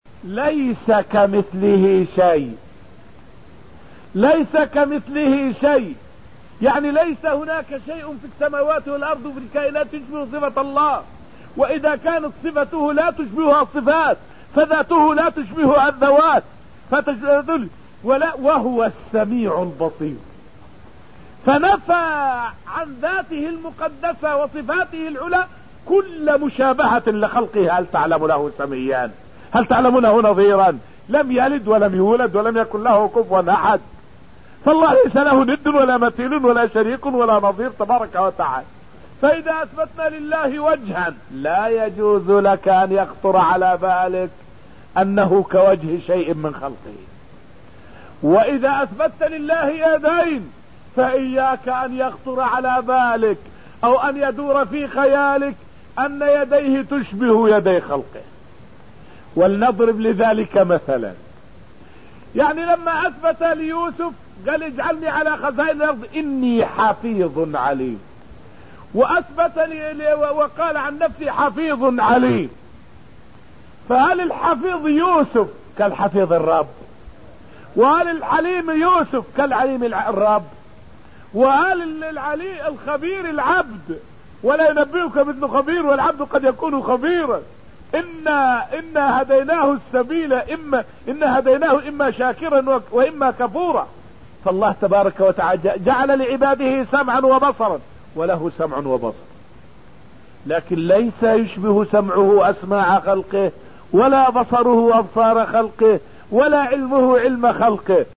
فائدة من الدرس الثامن من دروس تفسير سورة الرحمن والتي ألقيت في المسجد النبوي الشريف حول عدم جواز تشبيه صفات الخالق بصفات المخلوق.